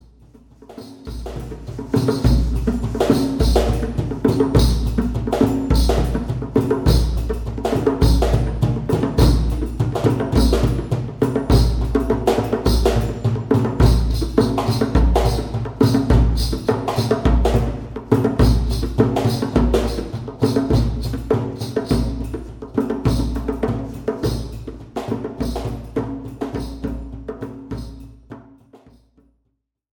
Percussion Solo 4